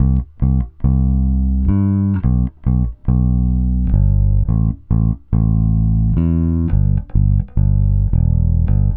Retro Funkish Bass 02b.wav